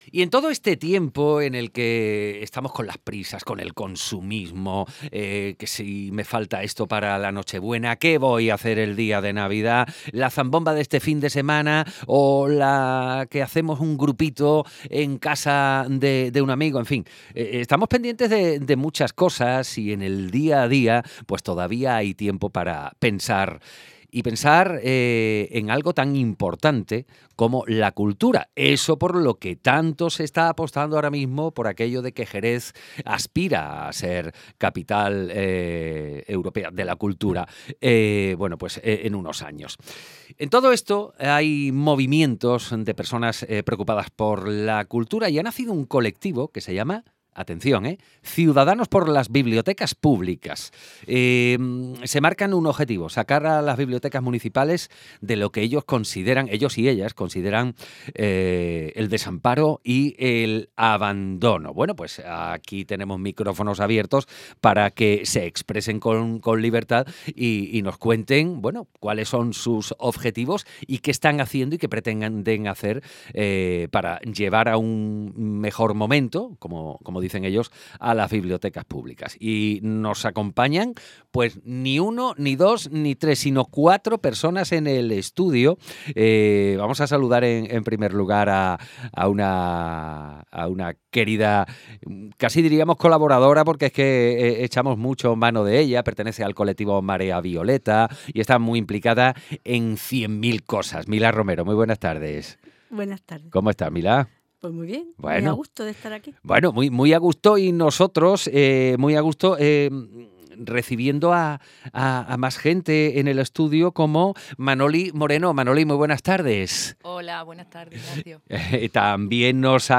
18-12_ENTREVISTA_CIUDADANOS_POR_LAS.BIBLIOTECAS1.mp3